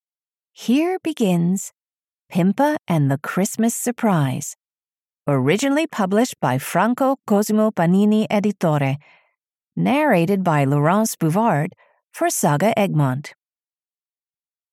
Pimpa and the Christmas Surprise (EN) audiokniha
Ukázka z knihy